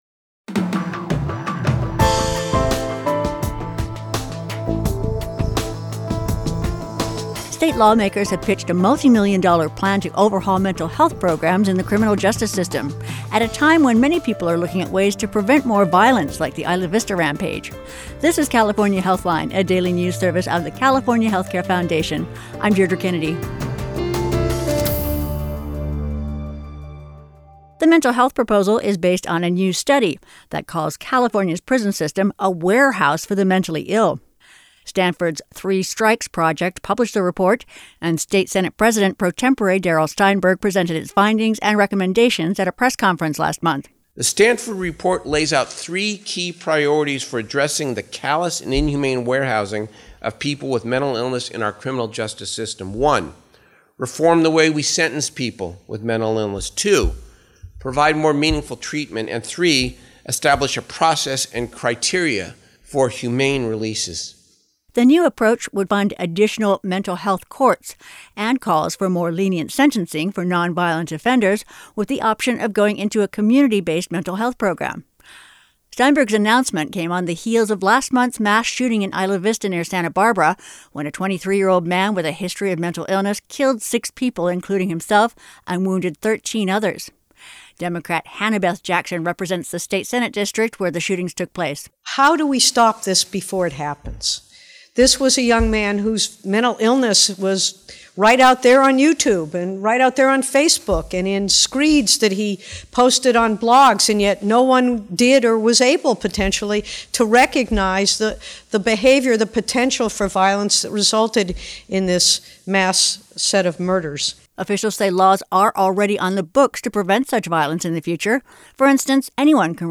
• Sen. Hannah-Beth Jackson (D-Santa Barbara);
Audio Report Insight Mental Health